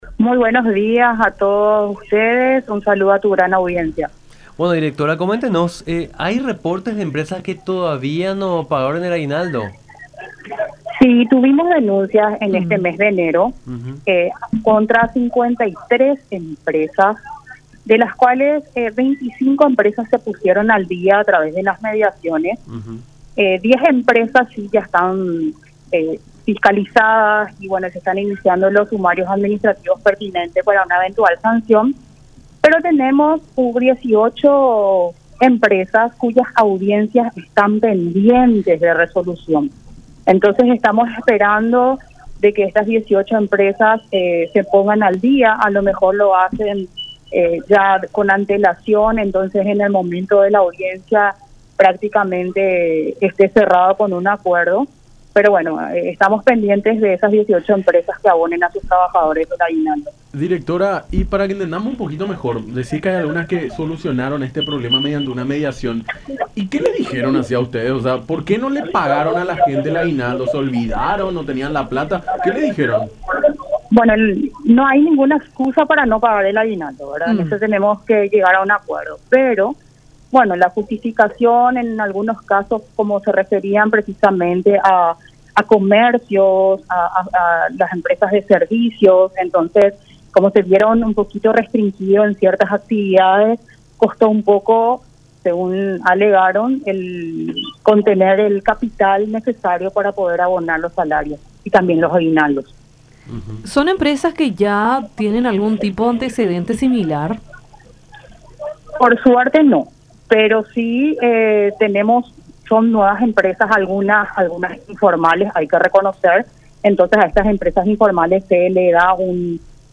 “Fueron unas 53 empresas las que fueron denunciadas por no haber abonado el aguinaldo a sus trabajadores. De esas 53, unas 25 se pusieron al día y ahora esperamos que las restantes también puedan hacer lo mismo”, dijo Karina Gómez, Directora del Trabajo, área perteneciente al Ministerio del Trabajo, en charla con Nuestra Mañana por La Unión.